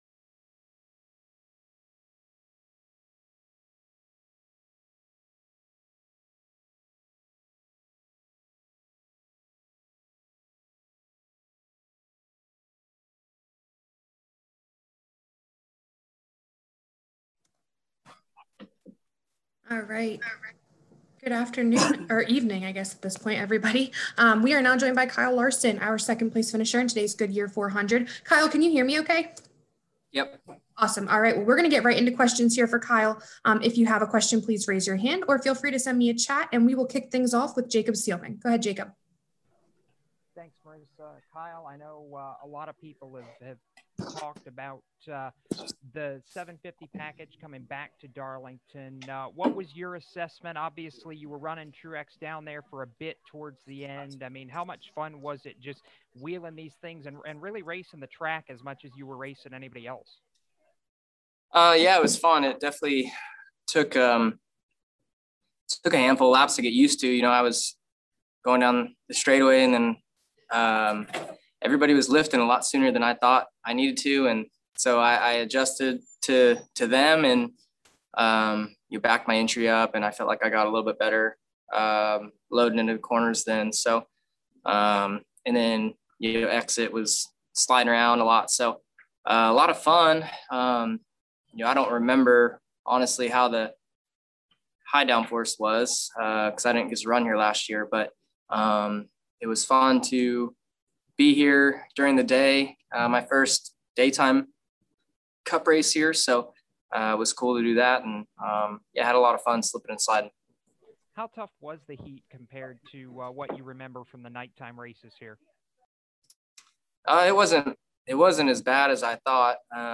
Interviews: